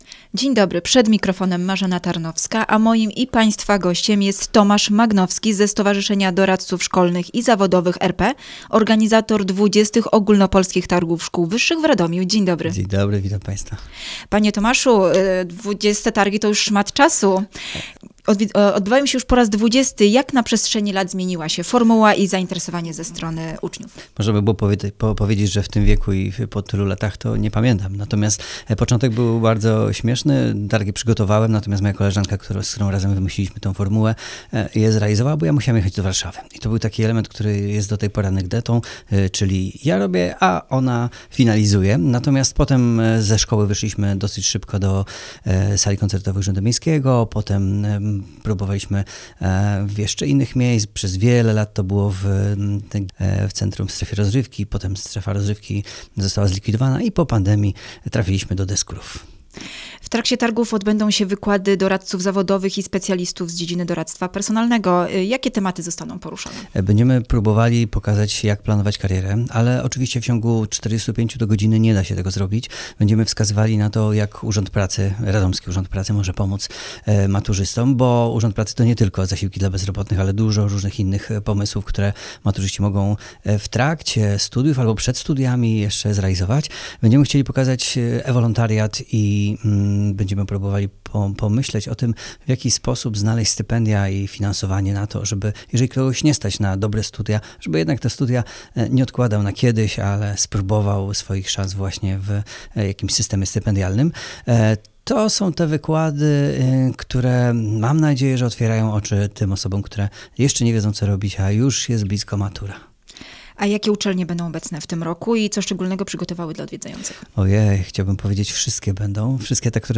w studiu Radia Radom